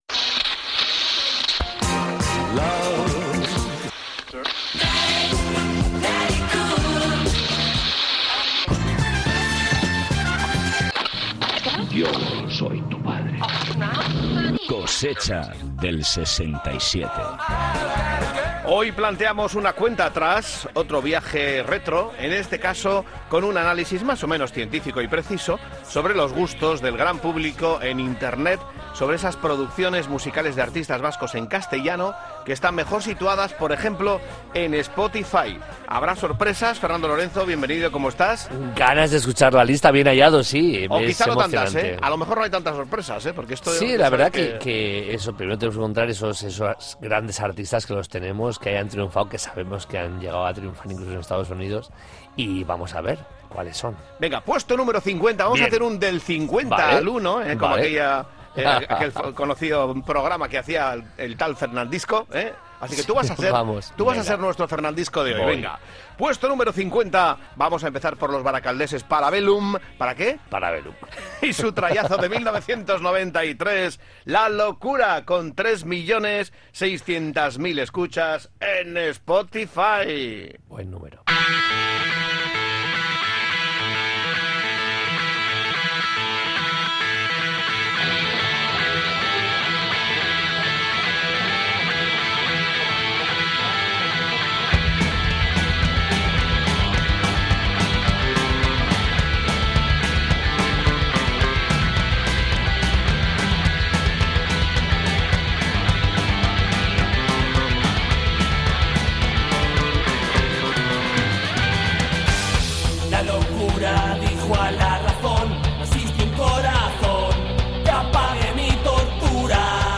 Espacio conectado a la nostalgia a través del humor y la música.